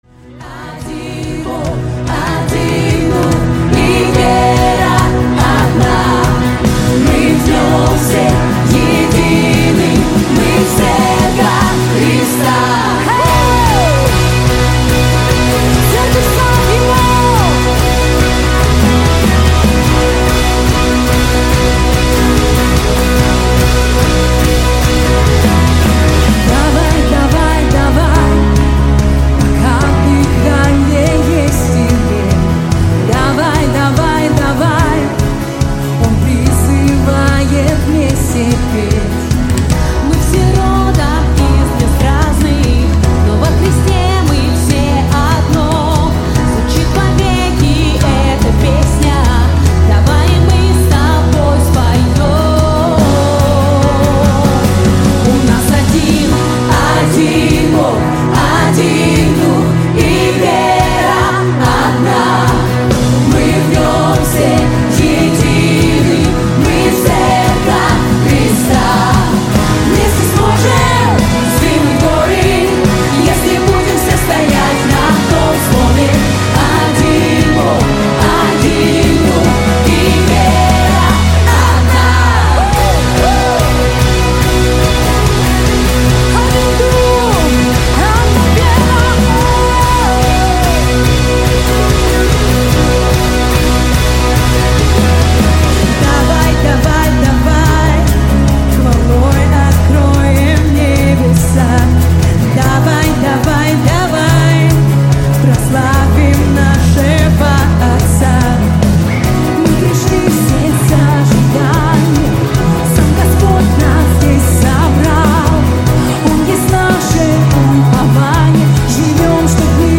136 просмотров 255 прослушиваний 8 скачиваний BPM: 145